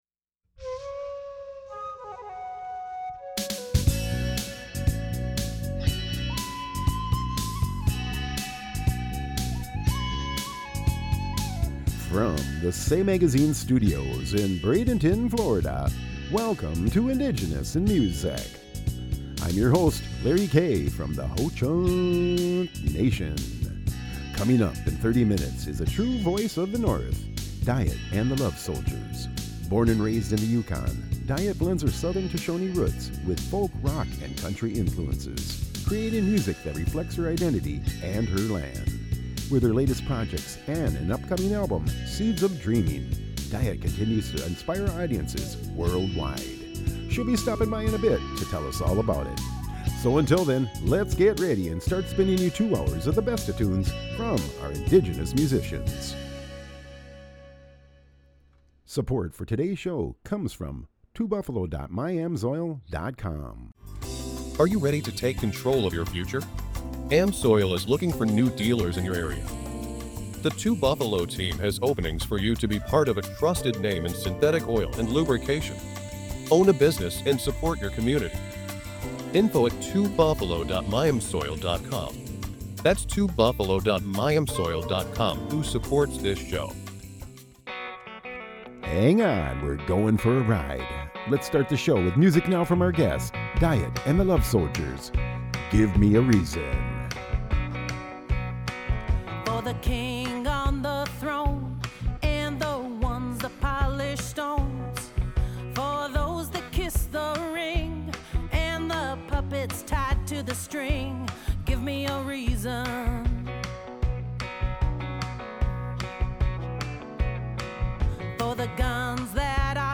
Spinning the Hottest Indigenous Hits and Artist Interviews